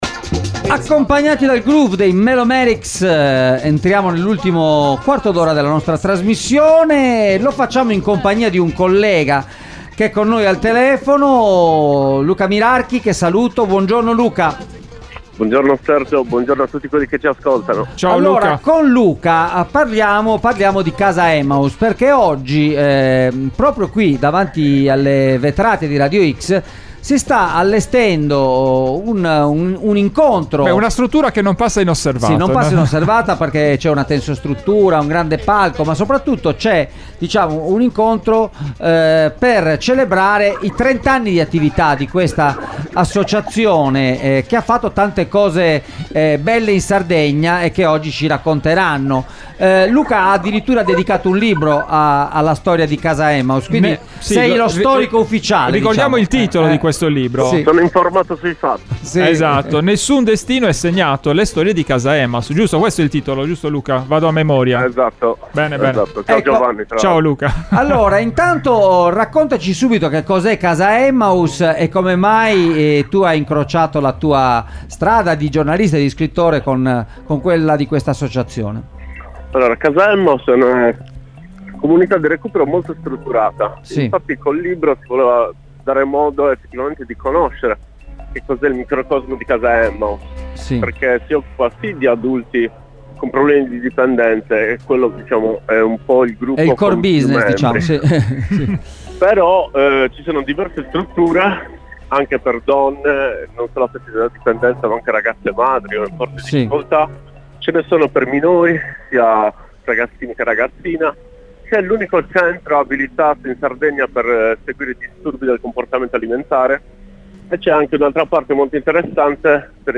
Trent’anni di Casa Emmaus – Intervista